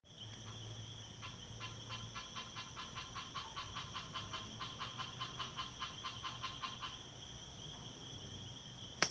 Ranita Hocicuda de Pecho Manchado (Scinax nasicus)
Nombre en inglés: Lesser Snouted Tree-frog
Fase de la vida: Adulto
Localidad o área protegida: Zárate
Condición: Silvestre
Certeza: Vocalización Grabada